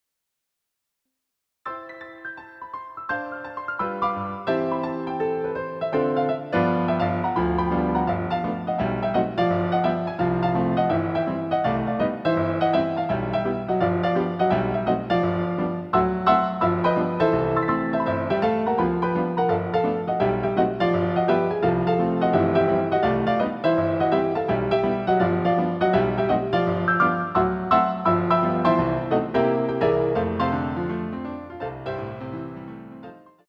CD quality digital audio
using the stereo sampled sound of a Yamaha Grand Piano.